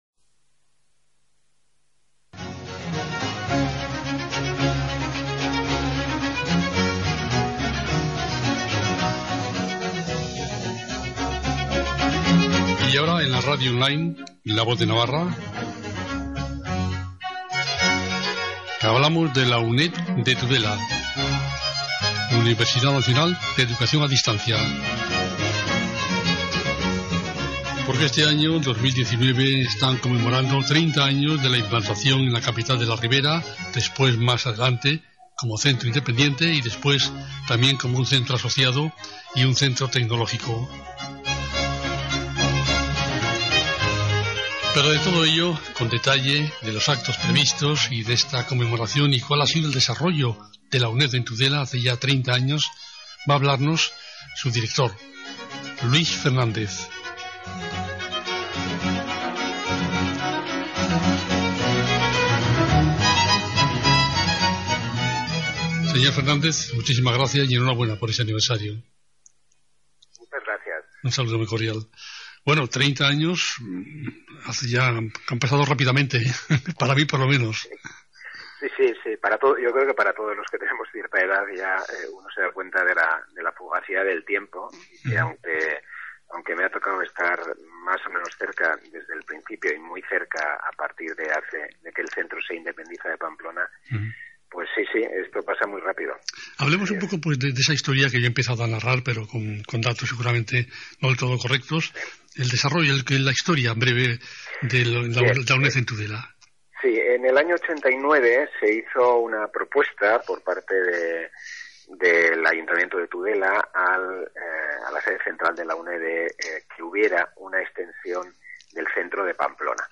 entrevista.mp3